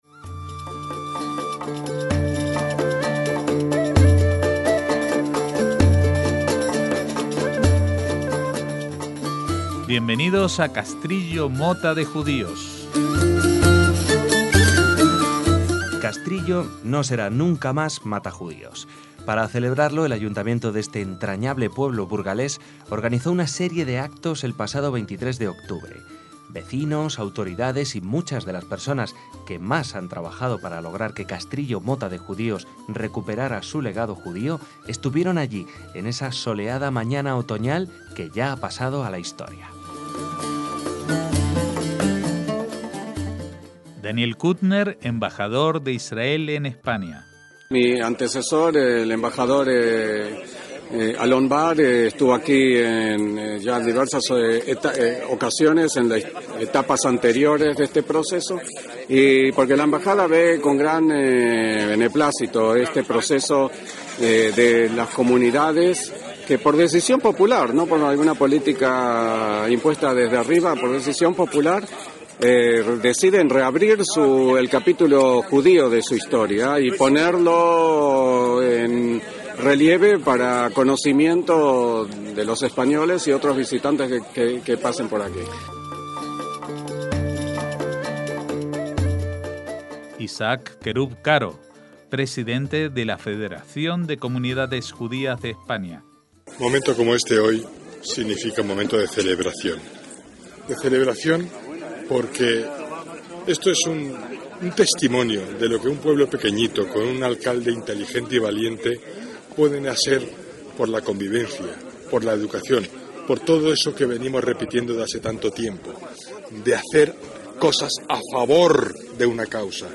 EL REPORTAJE - Les invitamos a compartir con nosotros un día feliz. Ese en el que Castrillo dejó de ser Matajudíos para siempre.